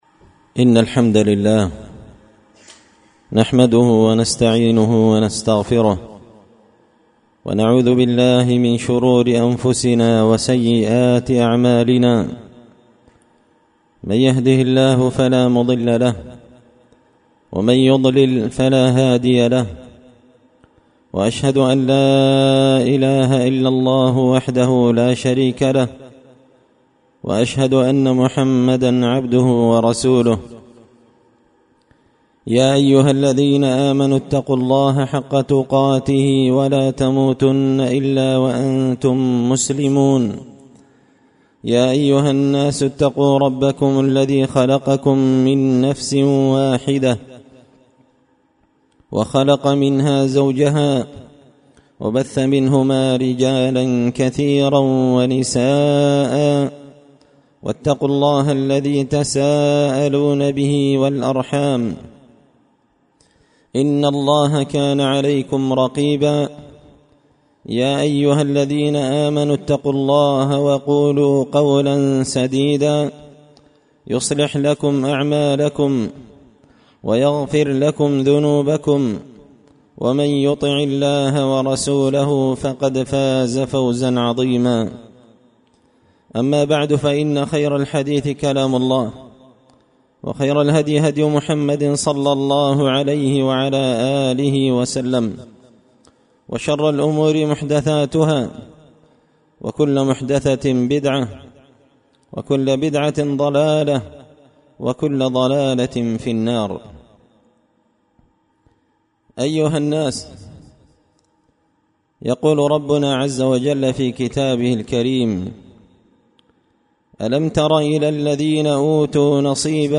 خطبة جمعة بعنوان – دفاع الصحابة الأخيار عن النبي المختار
دار الحديث بمسجد الفرقان ـ قشن ـ المهرة ـ اليمن